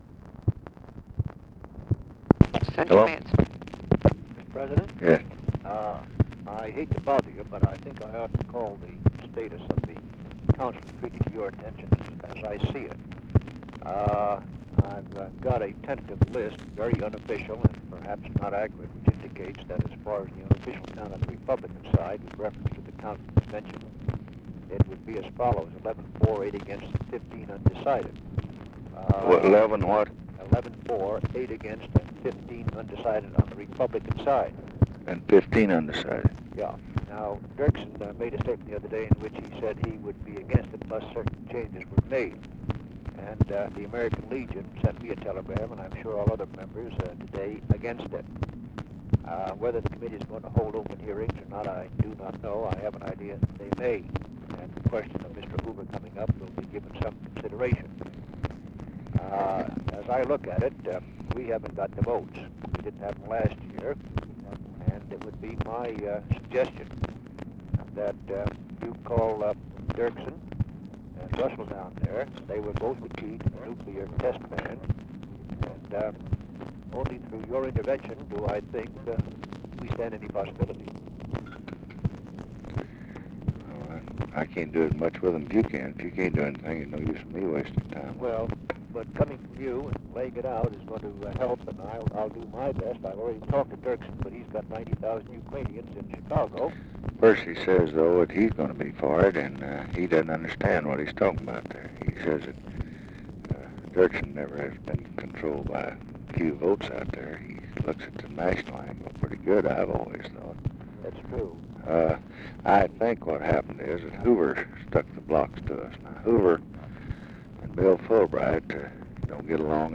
Conversation with MIKE MANSFIELD, January 25, 1967
Secret White House Tapes